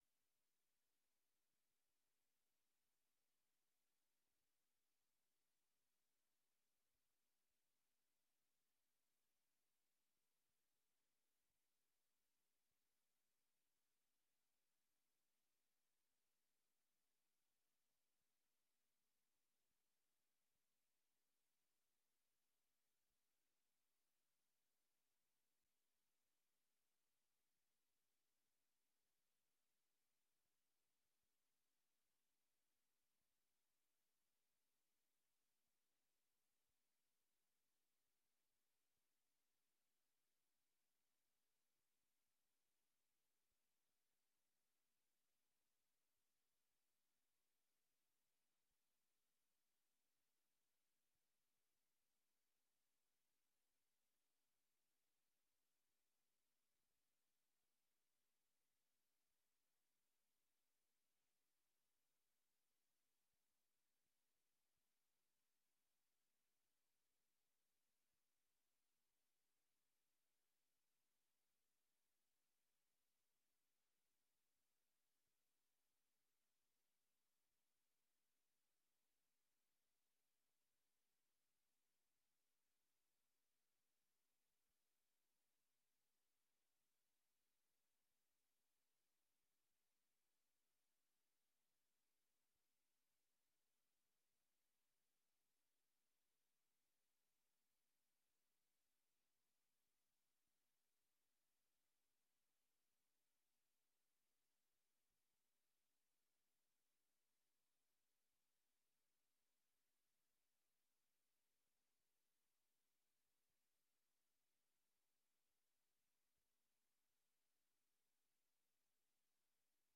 Raadsvergadering 07 juni 2023 19:30:00, Gemeente Dronten
Locatie: Raadzaal